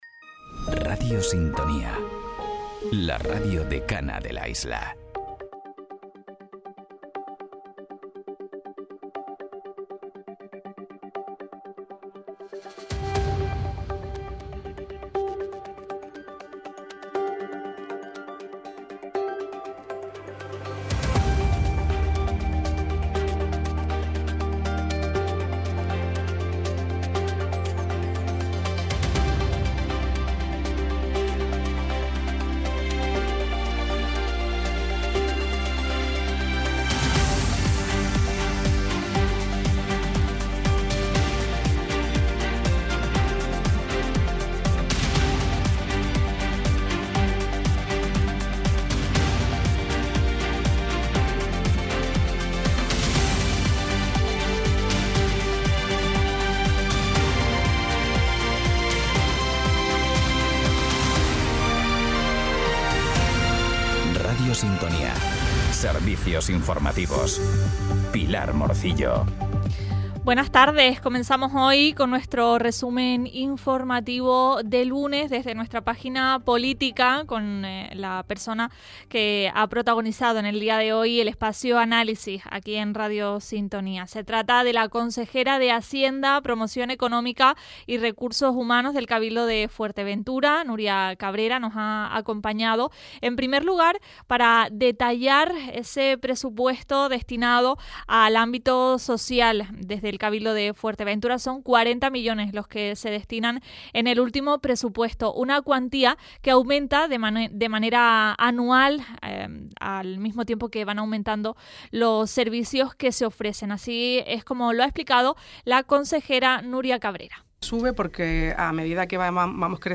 En él te contamos, en directo, las noticias más importantes de la jornada, a partir de las 13:15h.